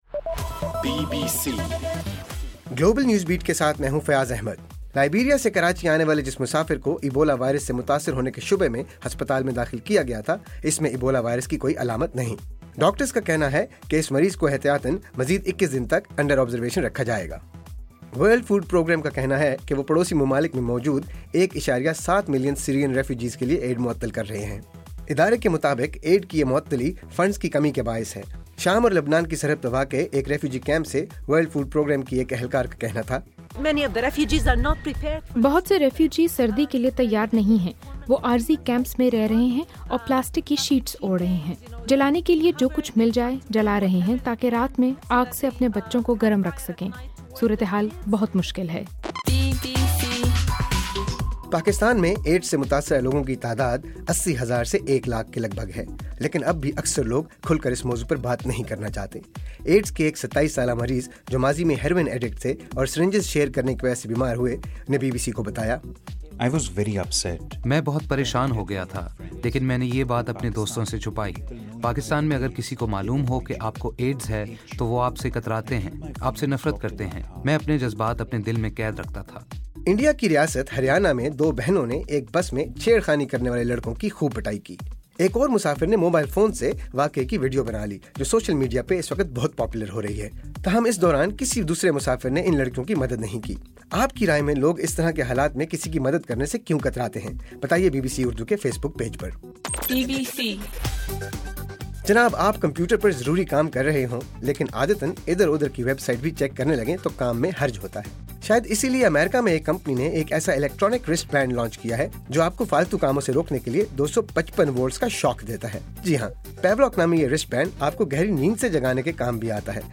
دسمبر 1: رات 12 بجے کا گلوبل نیوز بیٹ بُلیٹن